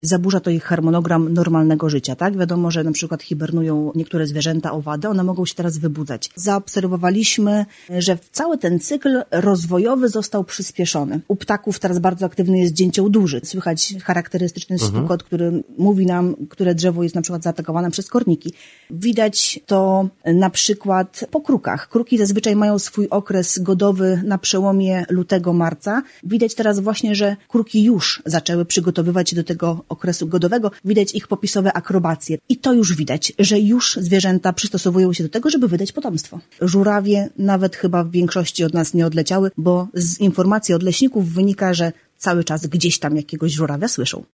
O wyjątkowo nietypowej aurze, jaką mamy tej zimy, rozmawialiśmy na antenie Radia 5